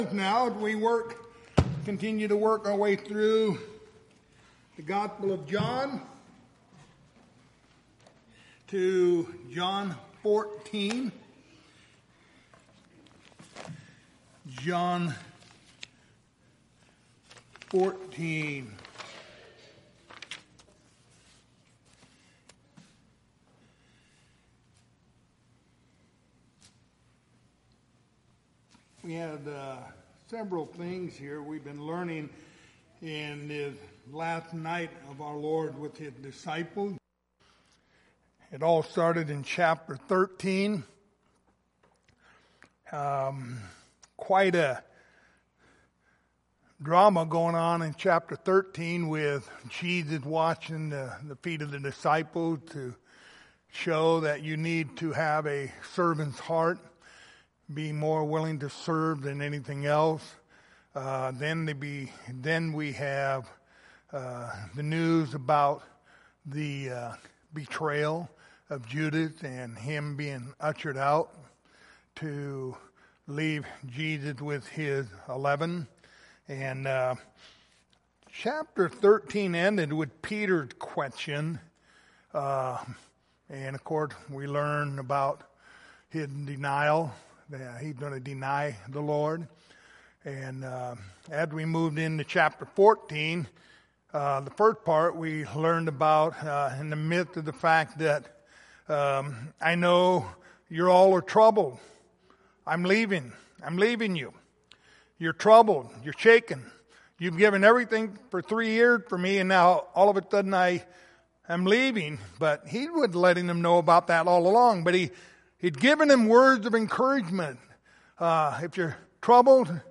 The Gospel of John Passage: John 14:1-11 Service Type: Wednesday Evening Topics